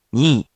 We’re going to show you the character, then you you can click the play button to hear QUIZBO™ sound it out for you.
In romaji, 「に」 is transliterated as 「ni」which sounds like「knee」